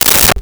Umbrella Opened 01
Umbrella Opened 01.wav